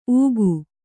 ♪ ūgu